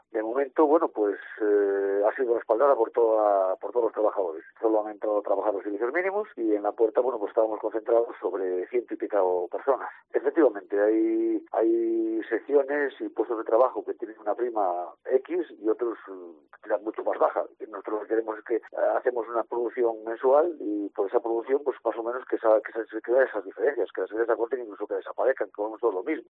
en declaraciones a COPE Bierzo